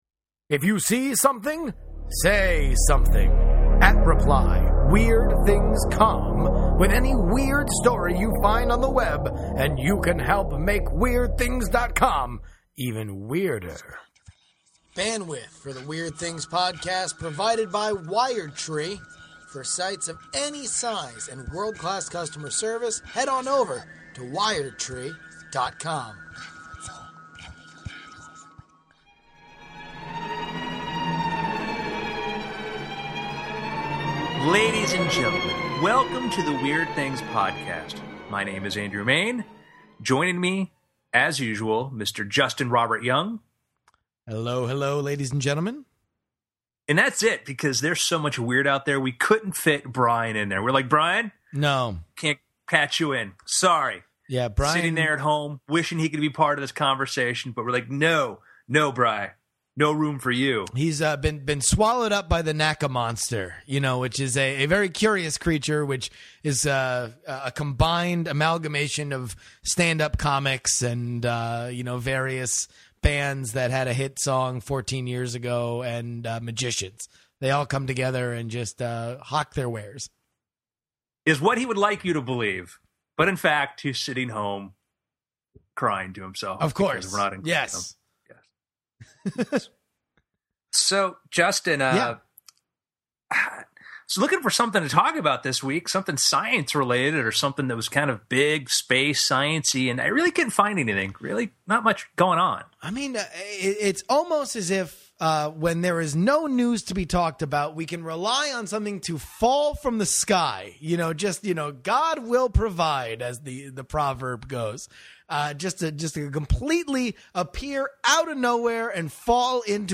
Then the yelling begins. Oh, the yelling.